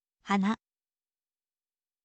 hana